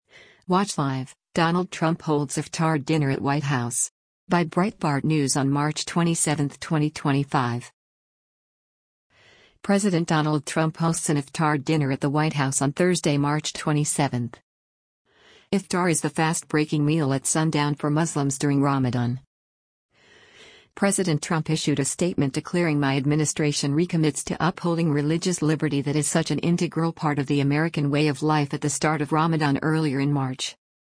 President Donald Trump hosts an Iftar dinner at the White House on Thursday, March 27.